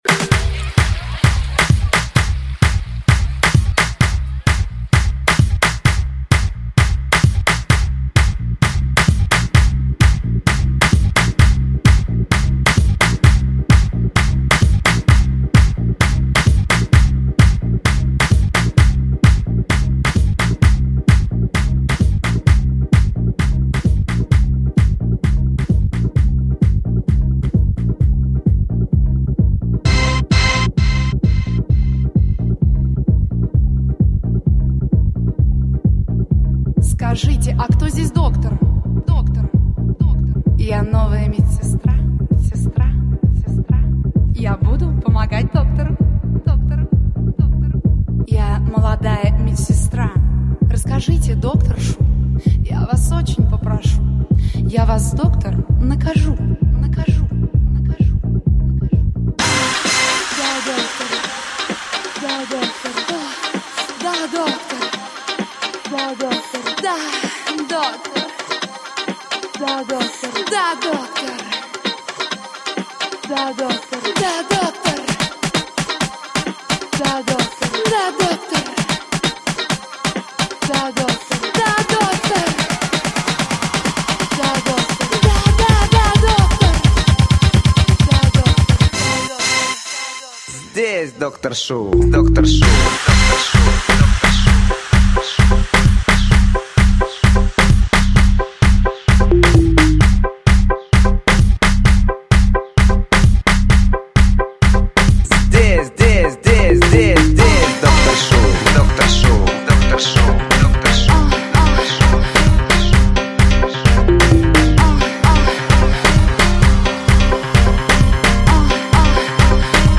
Главная » Каталог музыки » Клубная музыка